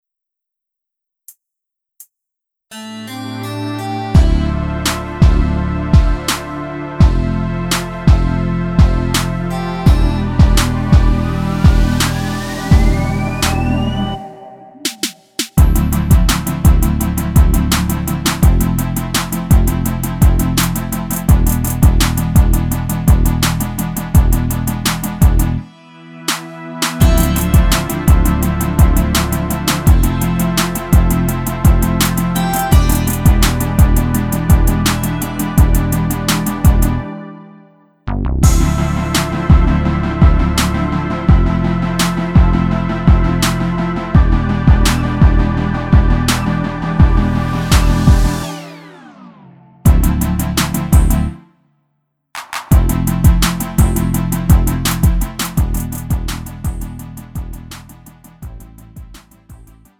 미리듣기
음정 원키
장르 가요 구분 Lite MR